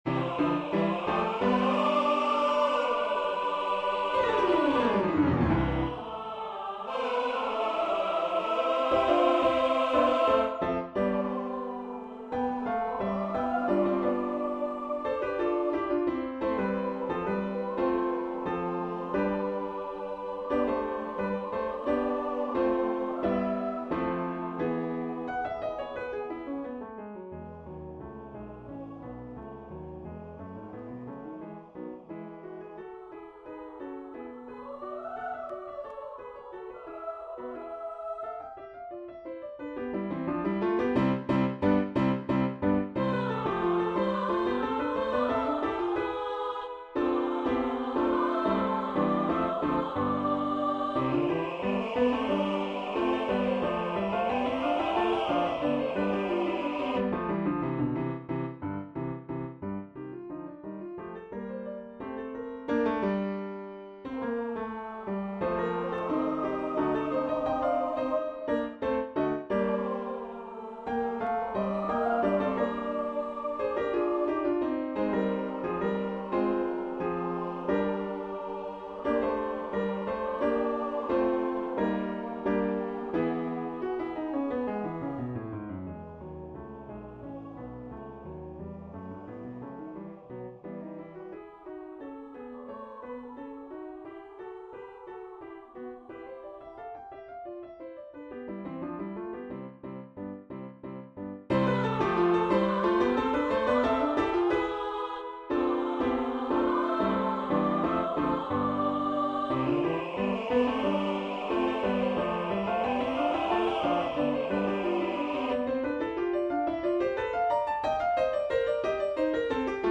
A lively student romp of the carpe diem type.
SATB chorus, piano
combines jazzy rhythms with florid vocal writing